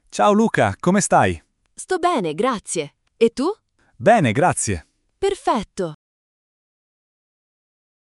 🗣 Dialogo 3 – Chiedere come stai